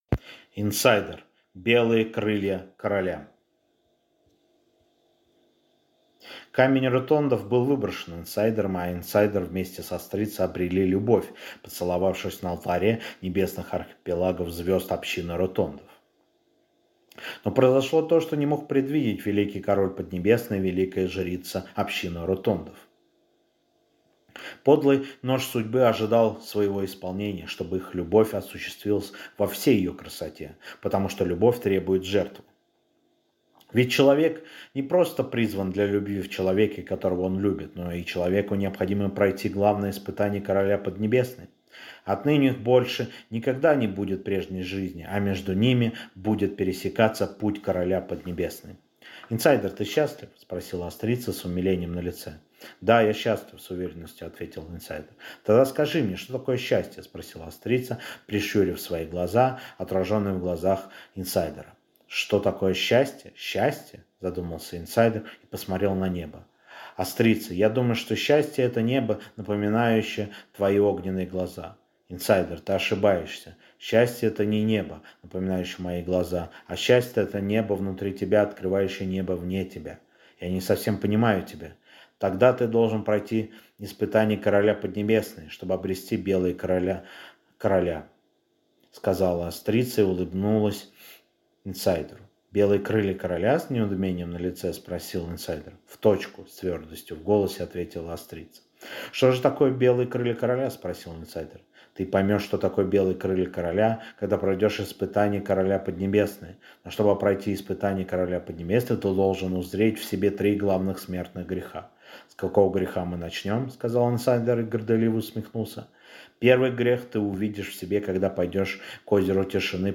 Аудиокнига Инсайдер. Белые Крылья Короля | Библиотека аудиокниг